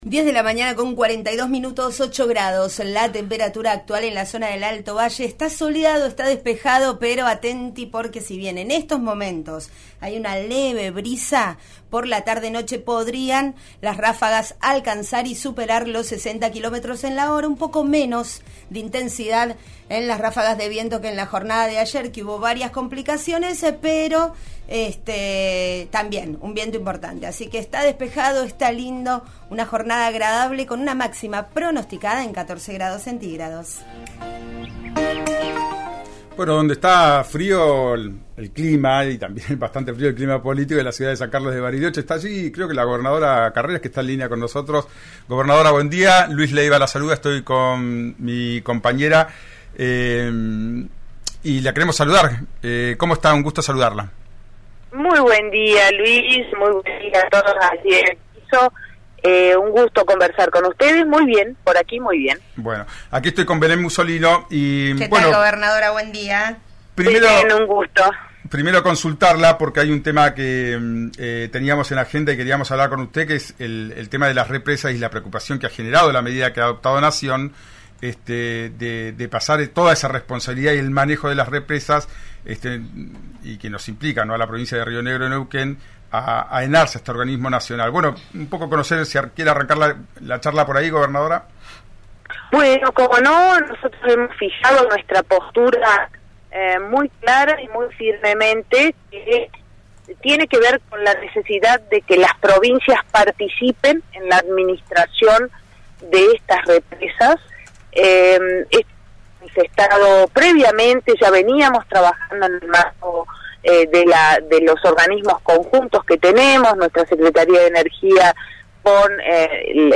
La gobernadora habló sobre JSRN y las elecciones en Bariloche. Adelantó que habrá reunión con Sergio Massa por las represas. La gobernadora habló en forma exclusiva con RÍO NEGRO RADIO sobre la interna, de cara al cierre de listas del lunes.